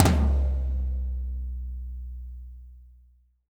-TOM 2N   -L.wav